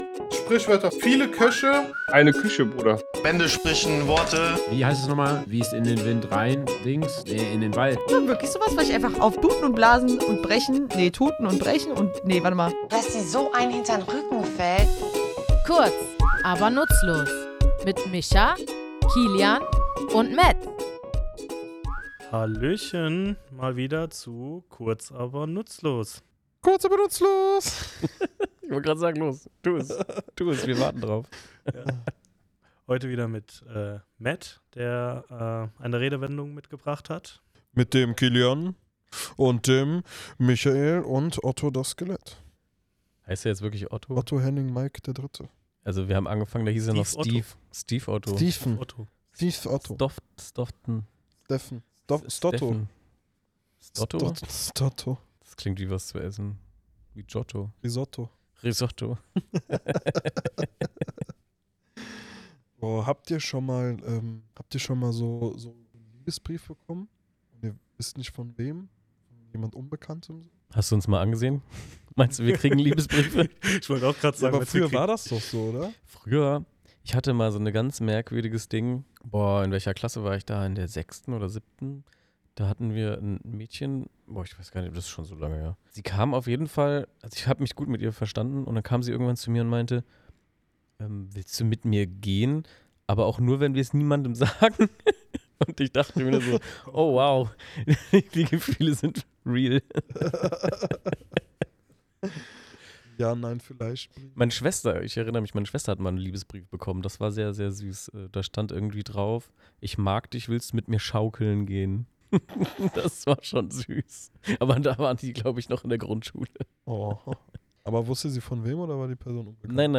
Beschreibung vor 1 Jahr Episodenbeschreibung für Folge 21: “Böhmische Dörfer” In der einundzwanzigsten Folge geht es um die Redewendung „böhmische Dörfer“. Was bedeutet es, wenn etwas völlig unverständlich ist, und warum ausgerechnet böhmische Dörfer? Wir, drei tätowierende Sprachforscher, klären in unserem Tattoostudio die Herkunft und die spannende Geschichte hinter diesem Ausdruck.